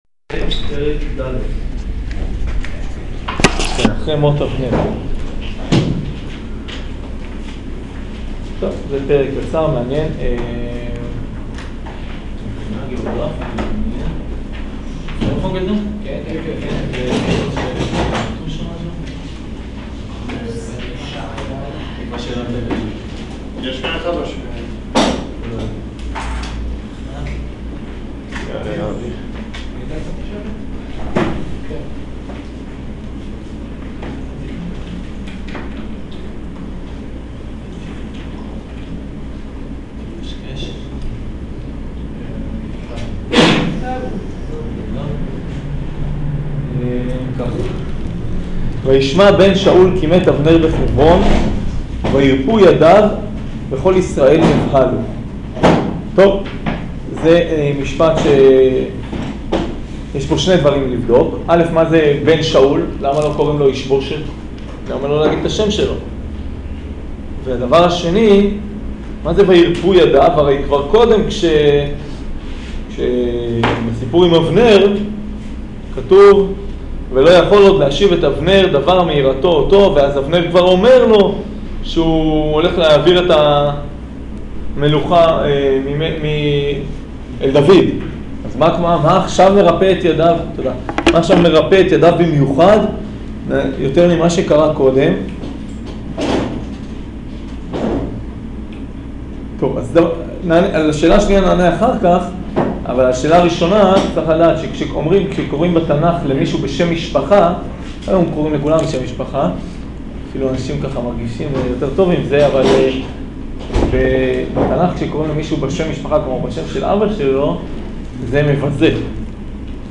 שיעור שמואל ב' פרק ד'